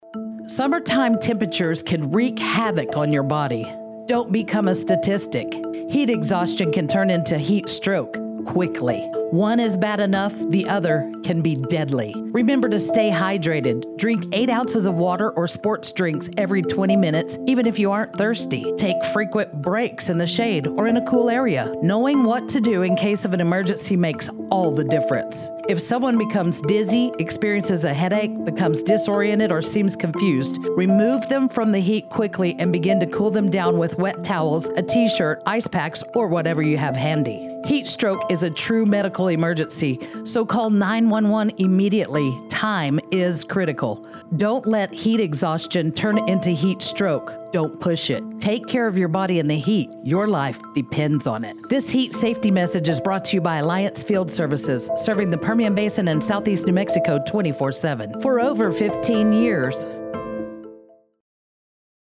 Alliance Field Services: Submitted a PSA radio ad on heat illness. The message raises awareness about heat illness, and shares steps individuals can take to render first aid immediately. The ad highlights Water, Rest, Shade and stresses the importance of time when dealing with heat illness.